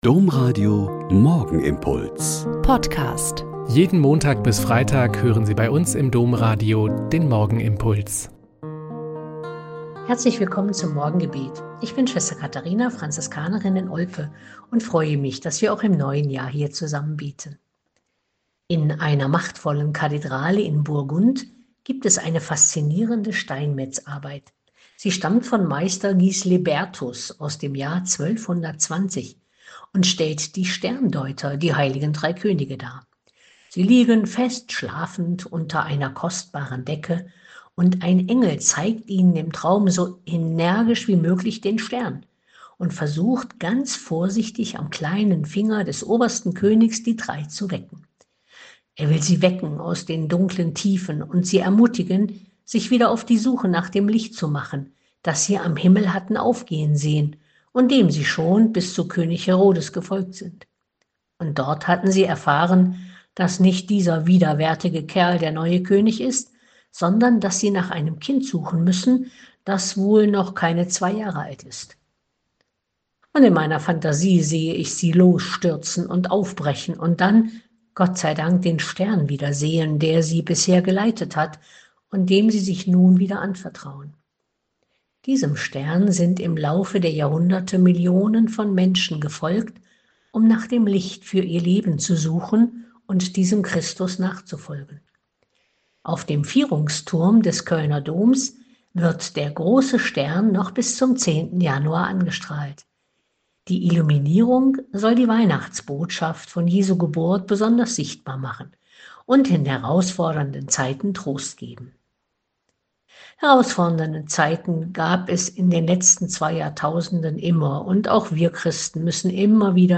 Mt 2,1-12 - Gespräch